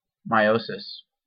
Meiosis (/mˈsɪs/
En-us-meiosis.ogg.mp3